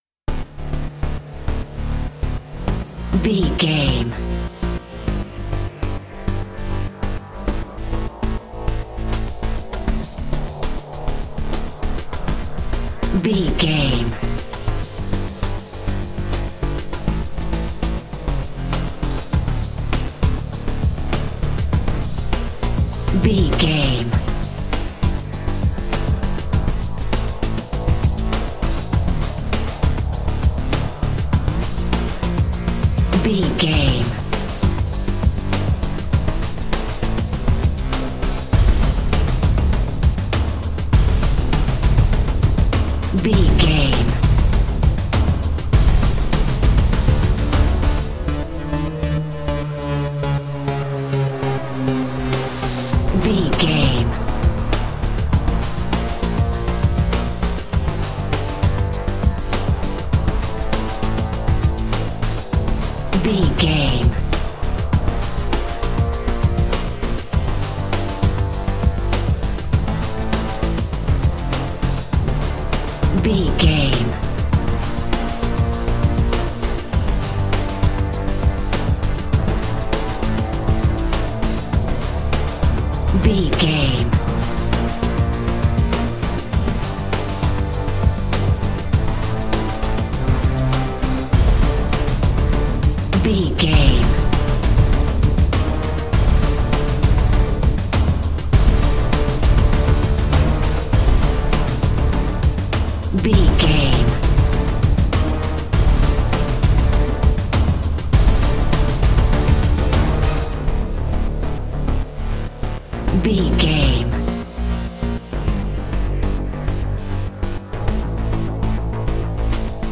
Epic / Action
Aeolian/Minor
strings
drum machine
synthesiser
brass
driving drum beat